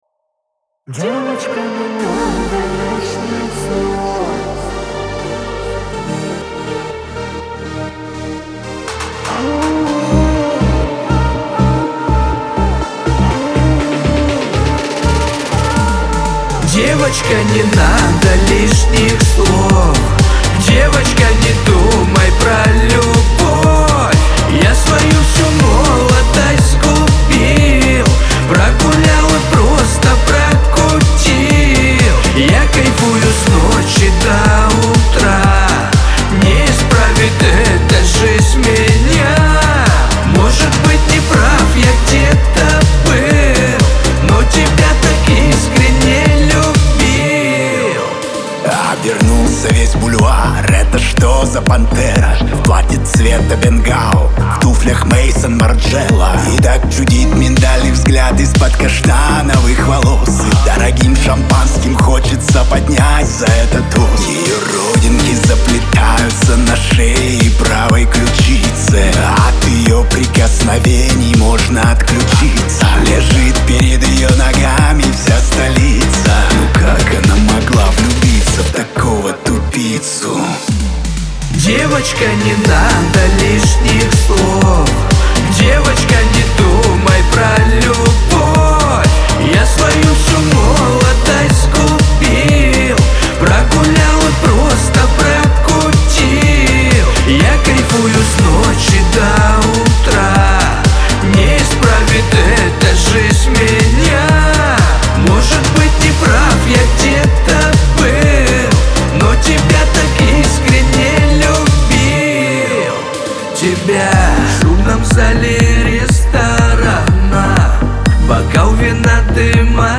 ХАУС-РЭП
эстрада